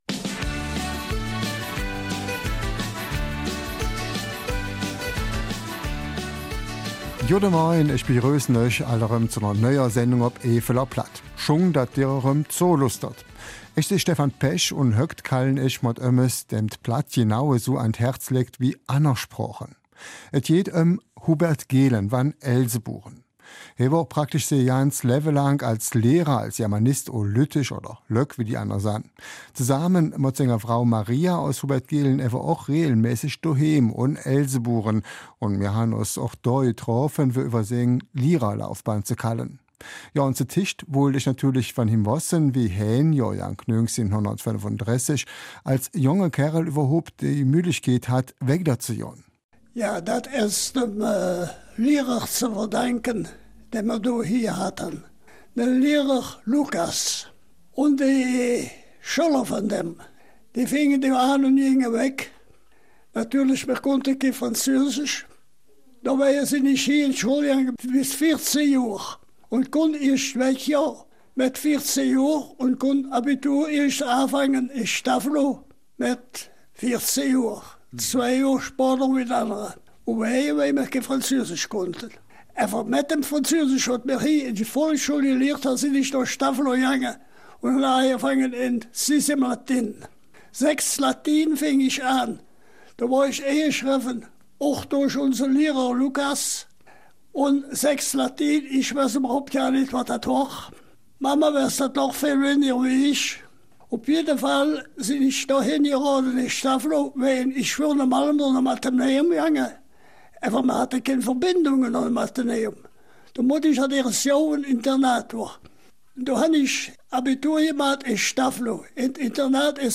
Eifeler Mundart: Aus dem Leben eines Lehrers